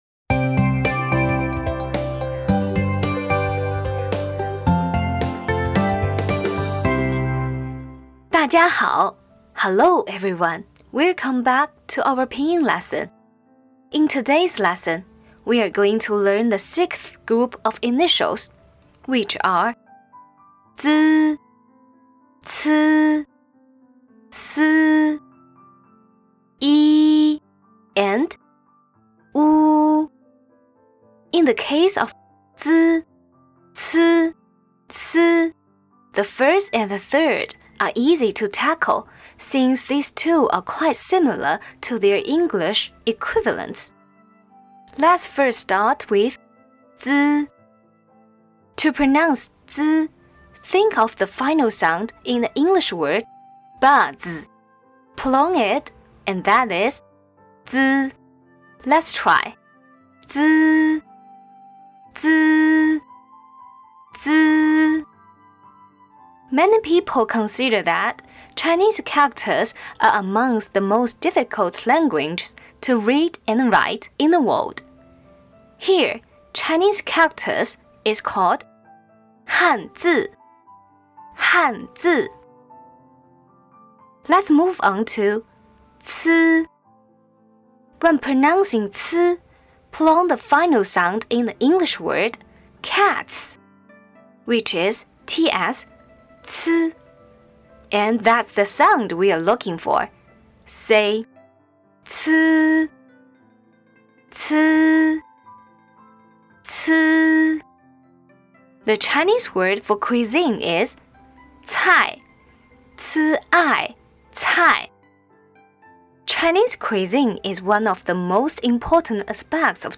Today we will show you how to pronounce the last 5 initials, which are z, c, s, y, w. To make it easier to memorize them, we will find some similar sounds in English.